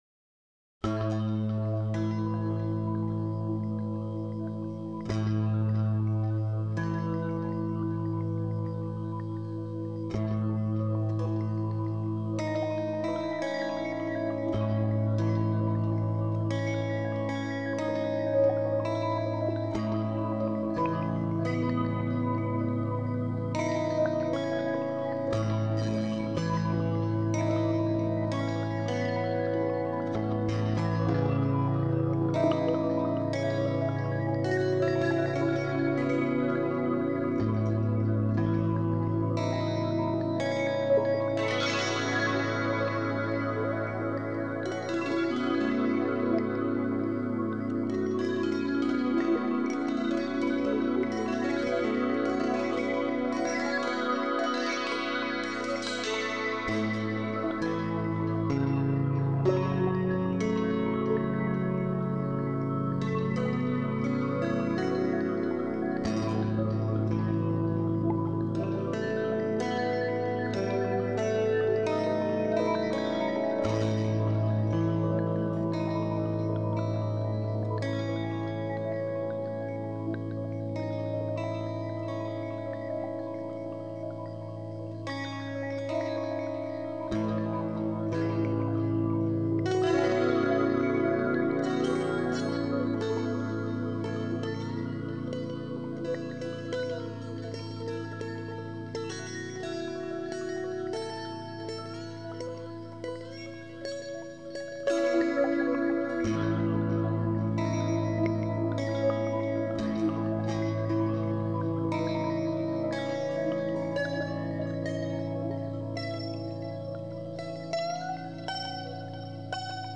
I decided to make three banks of five notes each, double strings for each note.
I wired simply all the pickups together, plugged into my Korg Pandora signal processing box, and recorded a little song.